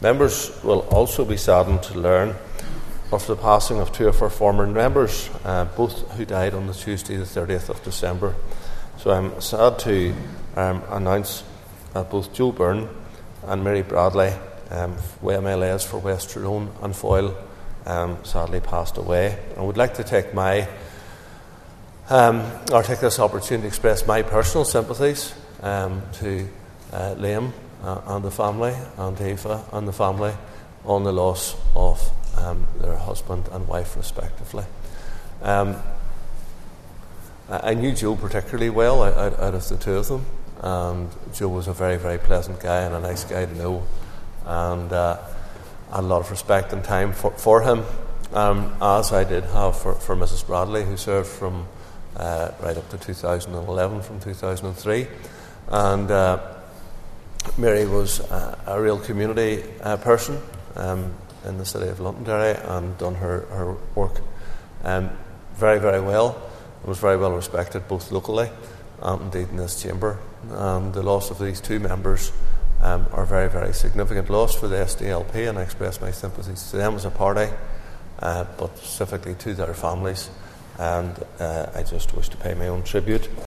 Tributes have been paid in the Northern Ireland Assembly this afternoon to Joe Byrne and Mary Bradley, two former MLAs who passed away the week after Christmas.
Speaker Edwin Poots said both had made a lasting and important contribution to politics in Northern Ireland………….